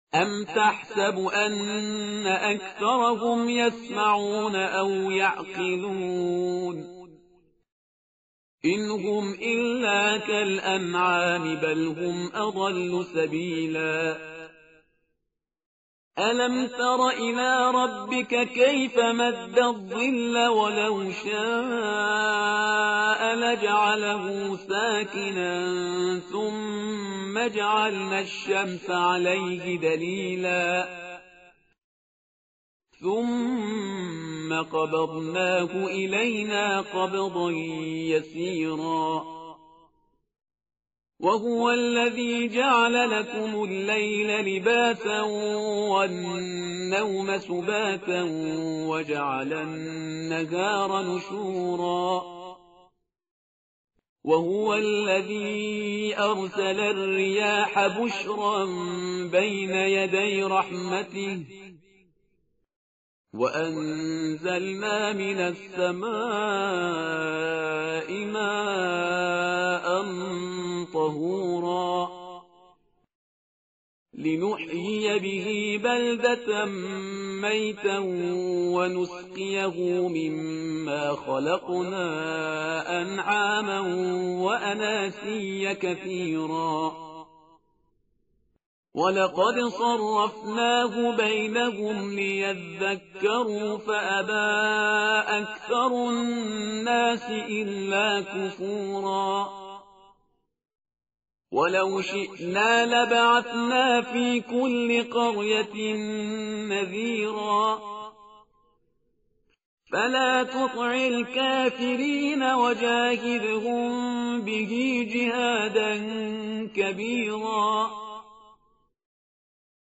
tartil_parhizgar_page_364.mp3